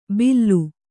♪ billu